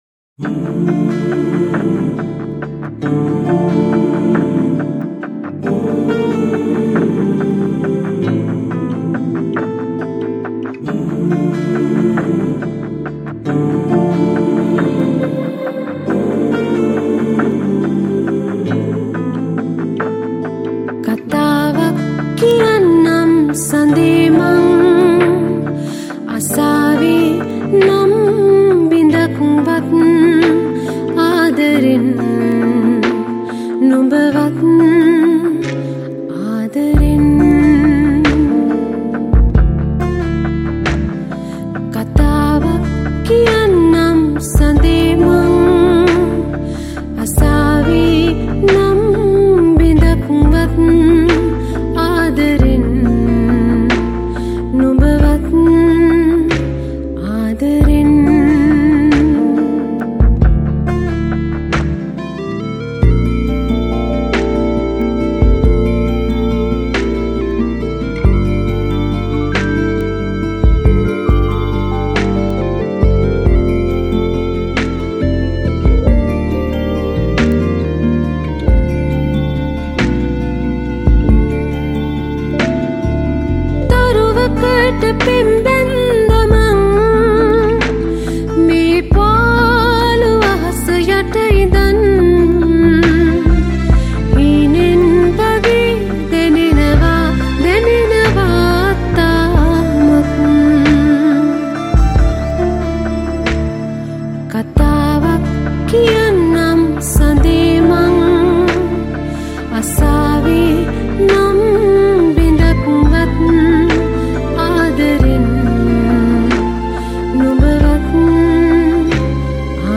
All Keys & Guitar